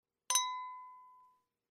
Звуки чоканья бокалов
Чоканье фужеров с игристым шампанским